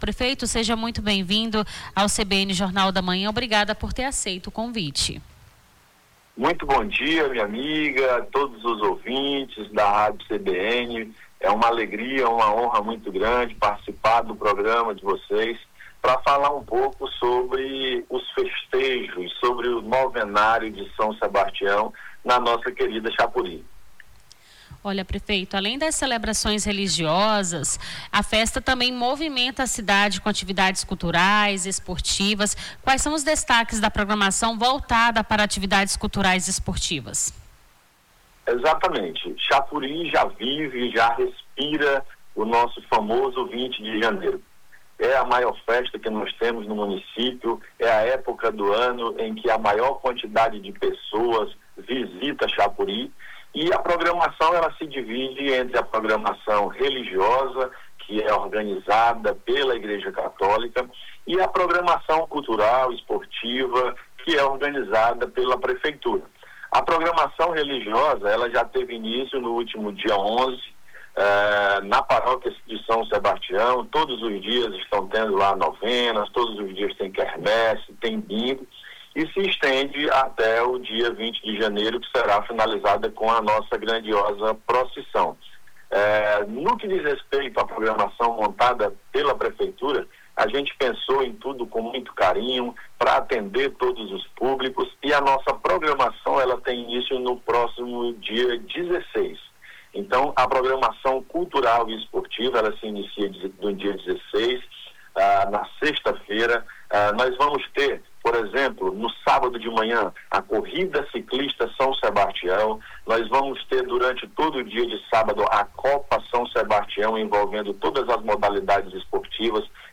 No Jornal da Manhã desta quarta-feira, 14, entrevistamos o prefeito de Xapuri, Maxsuel Maia Pereira, que detalhou a programação oficial da 124ª Festa de São Sebastião.